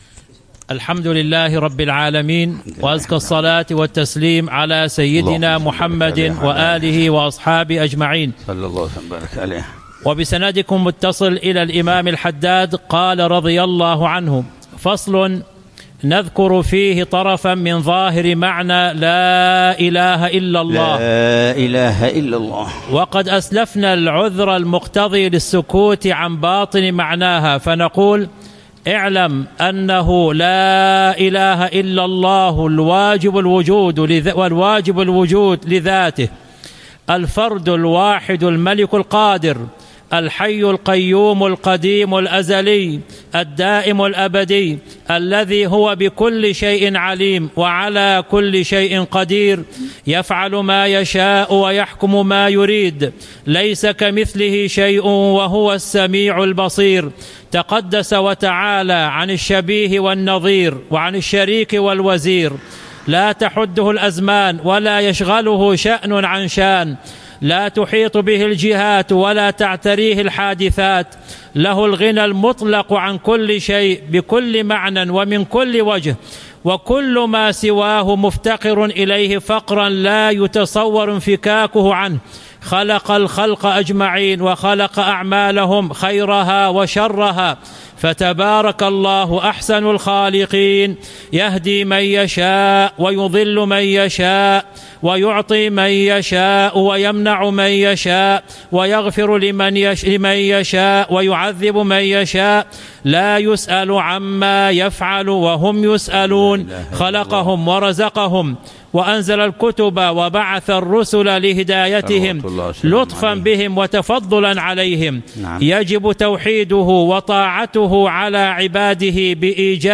شرح العلامة الحبيب عمر بن محمد بن حفيظ لكتاب إتحاف السائل بجواب المسائل، للإمام العلامة الحبيب عبد الله بن علوي الحداد رحمه الله، ضمن